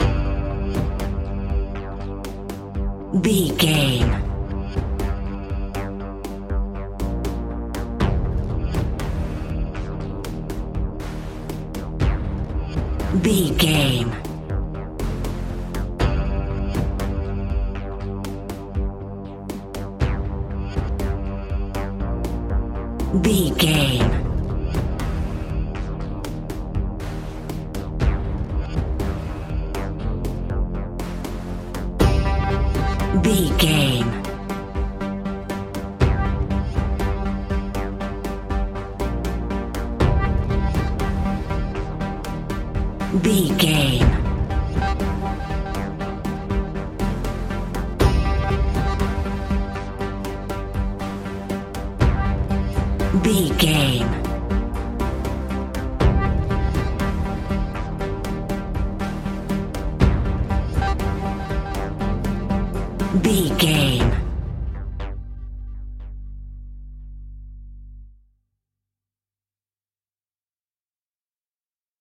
Aeolian/Minor
ominous
dark
eerie
synthesiser
drum machine
instrumentals
horror music